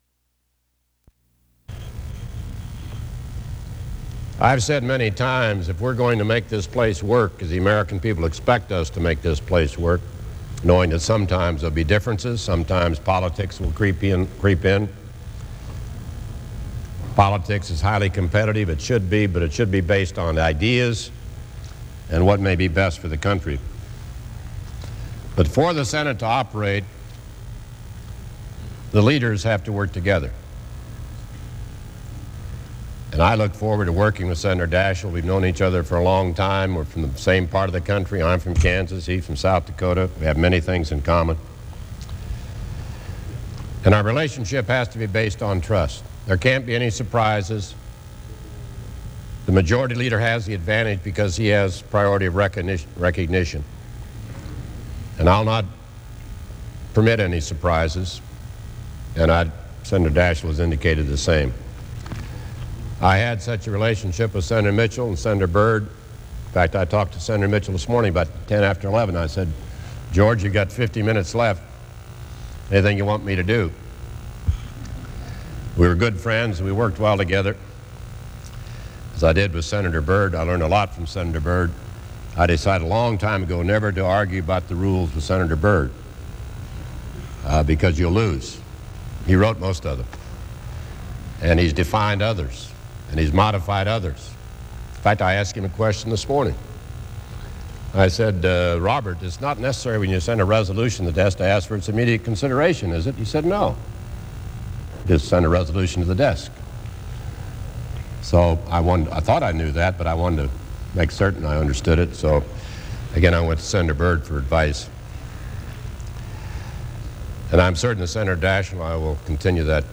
In his first speech as Senate Majority Leader, Robert Dole outlines the first of the bills the Republican majority will introduce